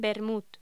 Locución: Vermut
voz